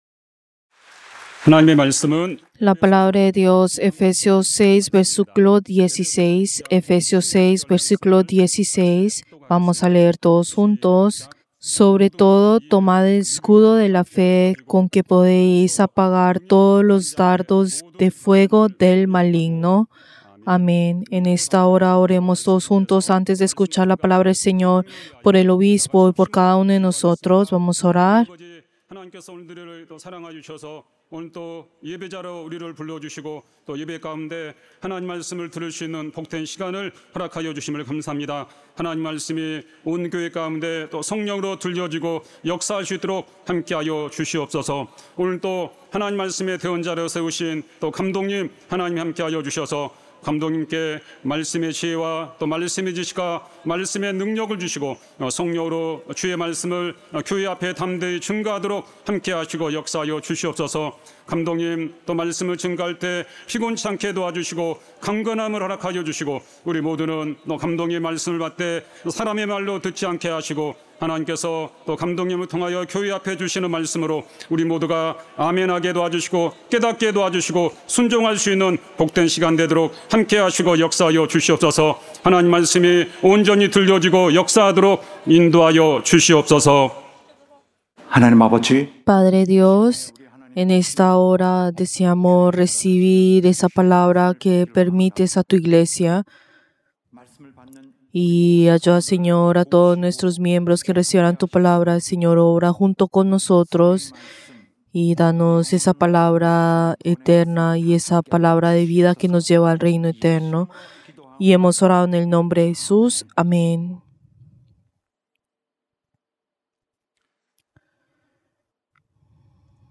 Servicio del Día del Señor del 20 de julio del 2025